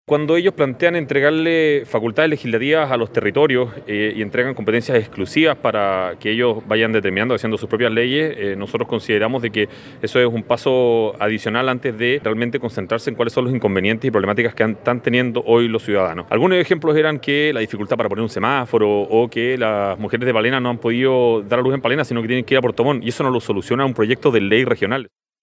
Al respecto se refirió el convencional por la UDI, Felipe Mena.